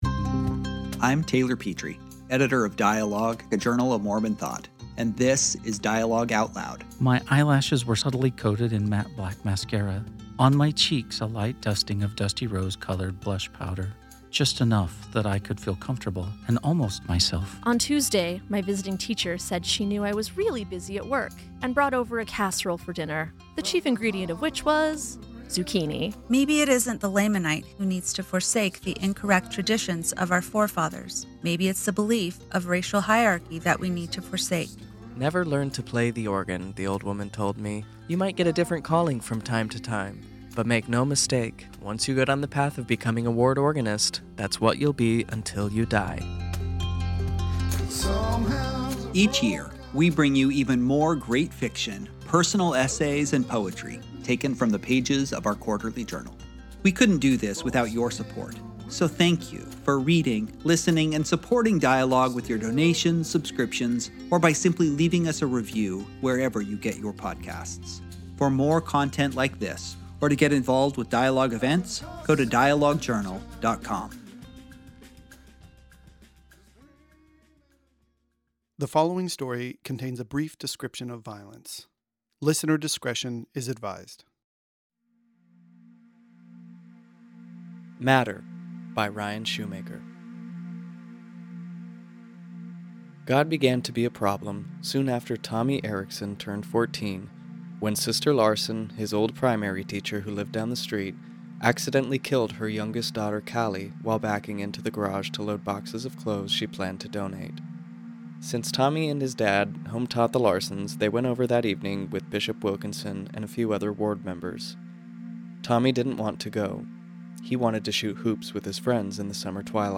a dramatic reading
Matter by Ryan Shoemaker: Audio Story